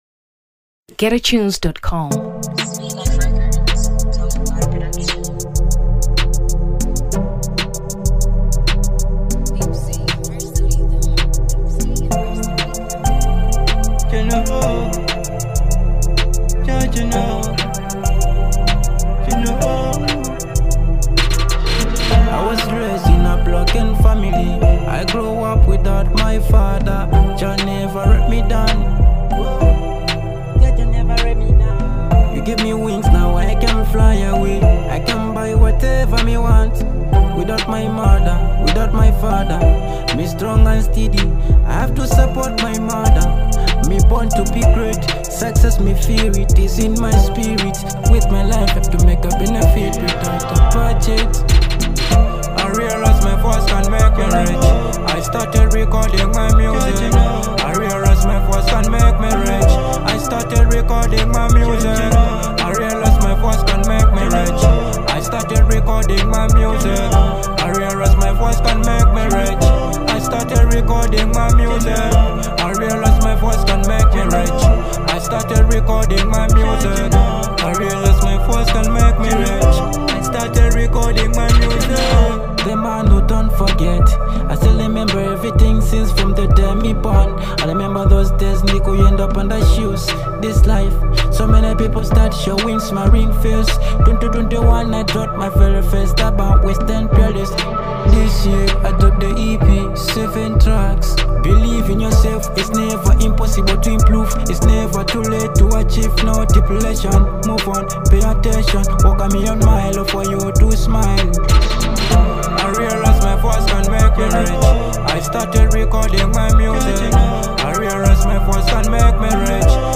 Afro Dancehall 2023 Malawi